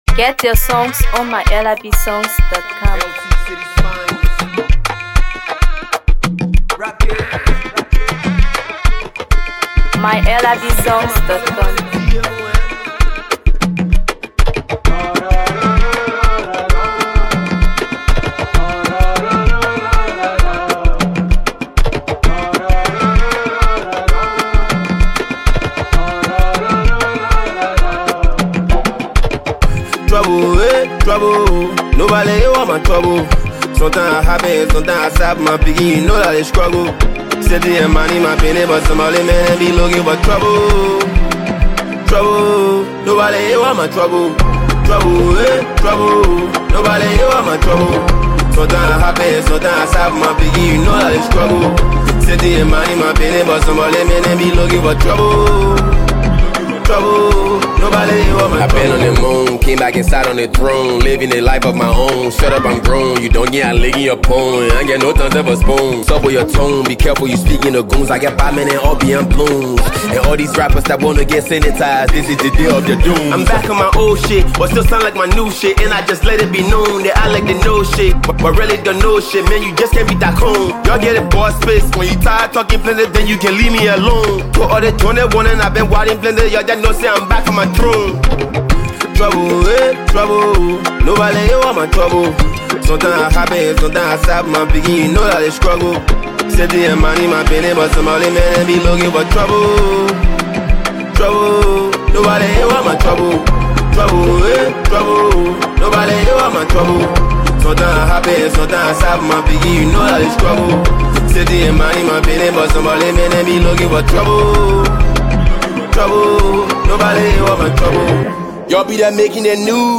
blending Hipco, Hip-hop, and Afro-fusion sounds.